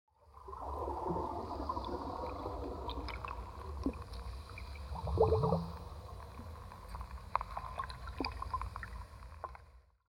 دانلود آهنگ دریا 20 از افکت صوتی طبیعت و محیط
جلوه های صوتی
دانلود صدای دریا 20 از ساعد نیوز با لینک مستقیم و کیفیت بالا